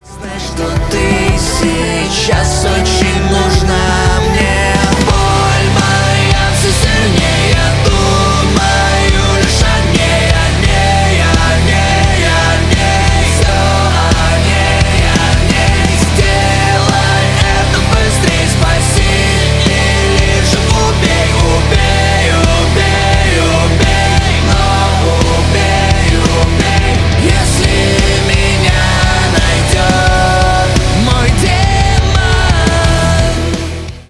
Рок Металл
громкие